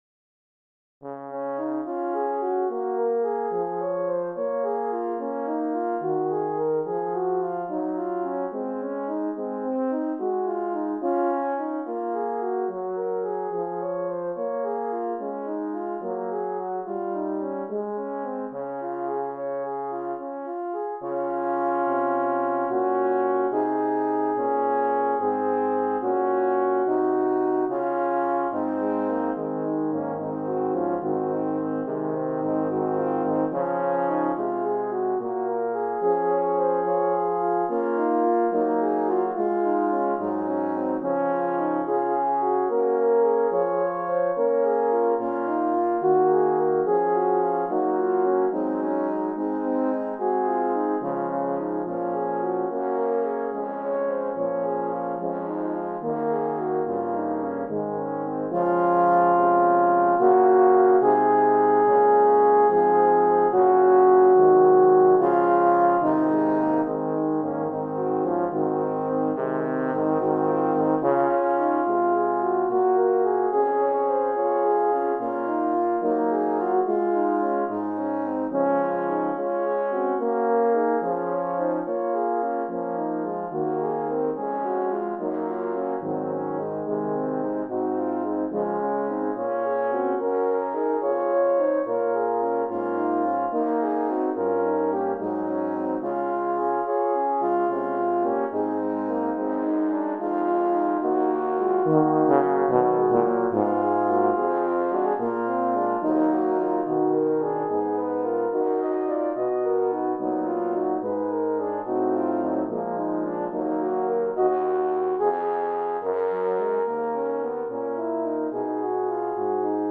Voicing: Horn Ensemble